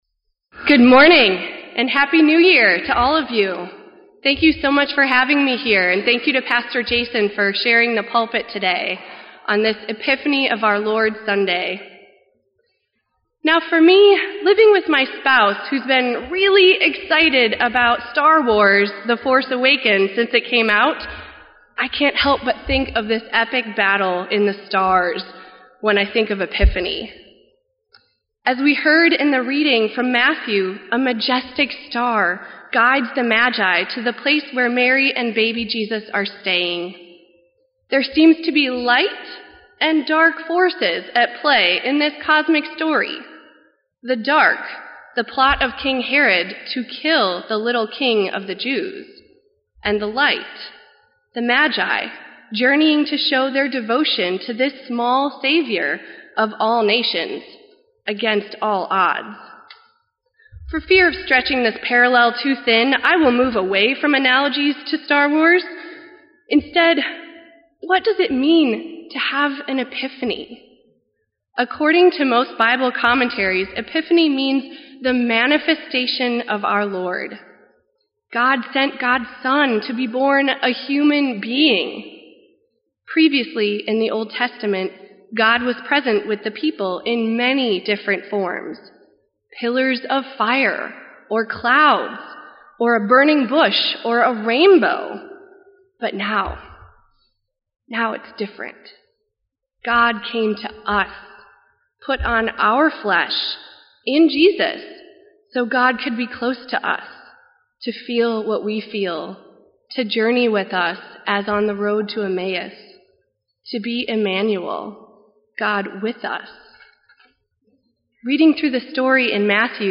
Epiphany of Our Lord Sunday Jan. 3, 2016 at Wicker Park Lutheran Church
Sermon_1_3_16.mp3